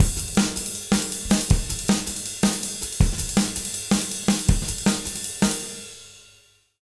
160JUNGLE2-L.wav